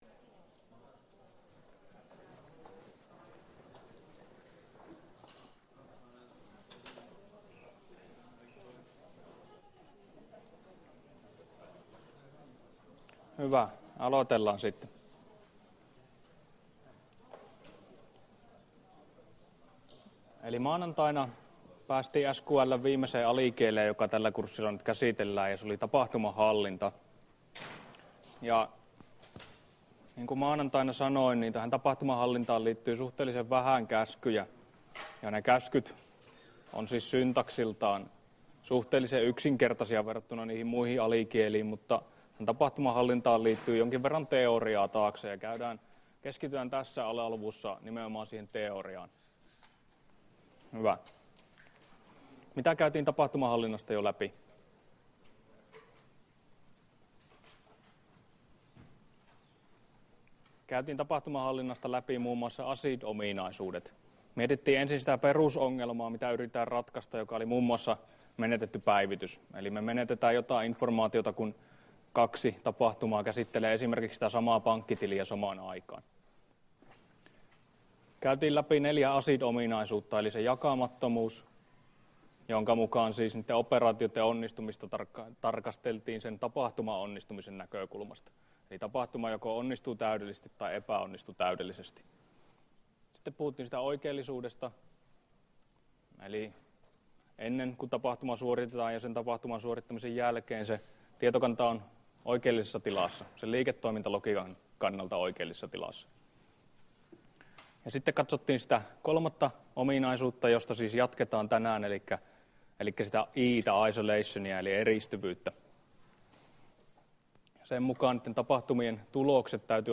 Luento 10 — Moniviestin